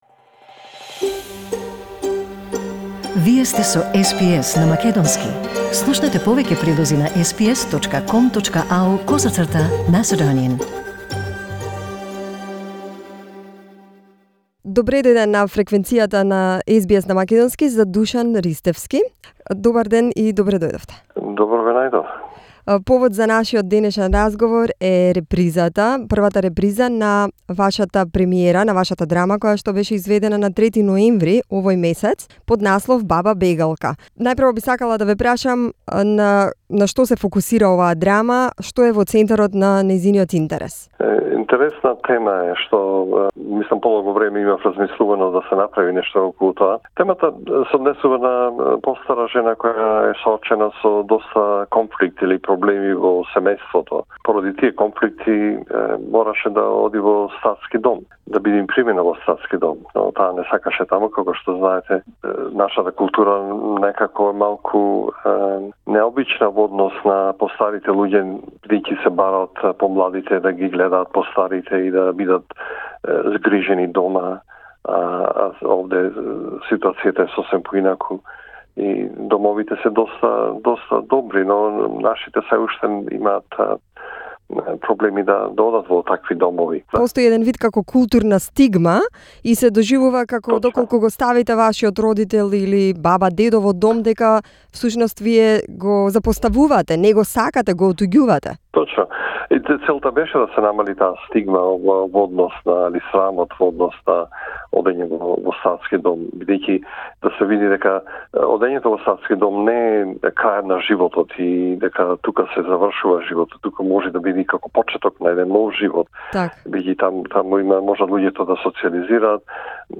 Австралиски хор пее македонски народни песни во комедијата "Баба бегалка"